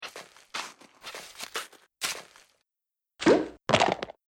resources/phase_5/audio/sfx/teleport_disappear.mp3 at e1639d5d6200f87d3312cfc8c8d1bbb1f69aad5f
teleport_disappear.mp3